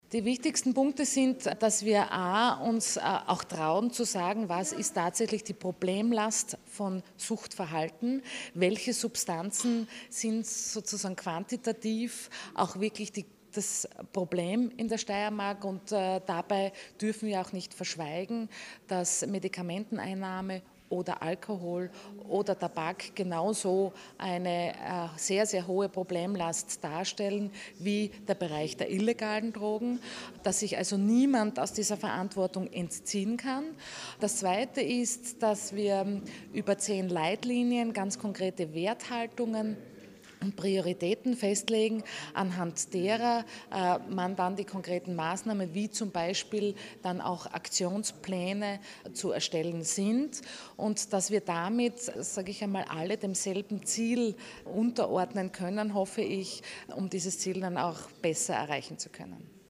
O-Ton: Die neue steirische Suchtpolitik
Gesundheitslandesrätin Kristina Edlinger-Ploder: